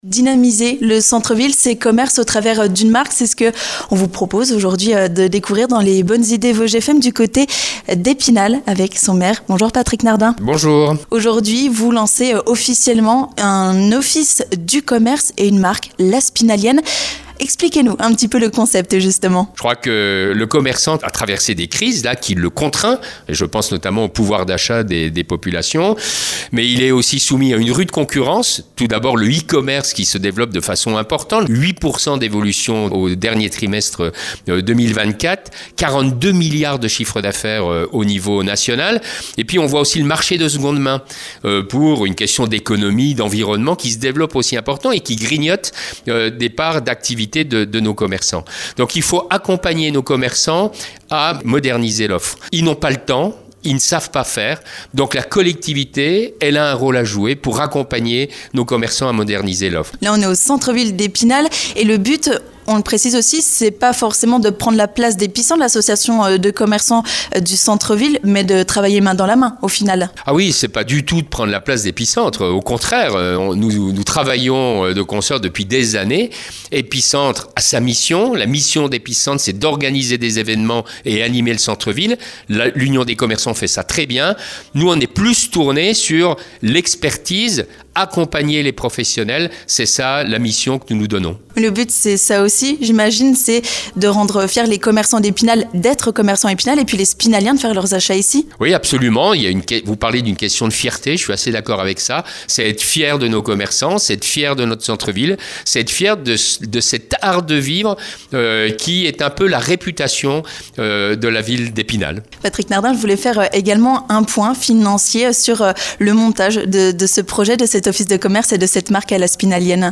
Dans les Bonnes Idées Vosges FM, Patrick Nardin nous explique quel est le but précis de cette marque et de cet office des commerces.